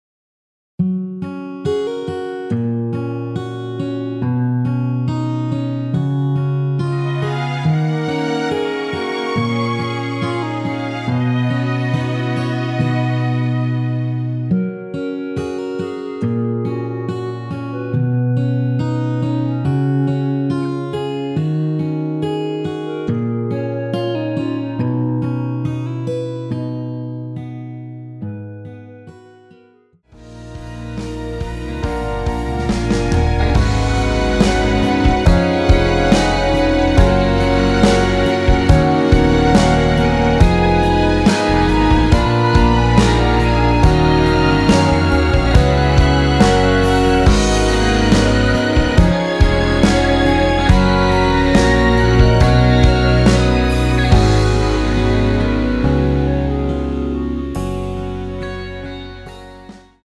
원키에서(+4)올린 멜로디 포함된 MR 입니다.(미리듣기 참조)
F#
노래방에서 노래를 부르실때 노래 부분에 가이드 멜로디가 따라 나와서
앞부분30초, 뒷부분30초씩 편집해서 올려 드리고 있습니다.
중간에 음이 끈어지고 다시 나오는 이유는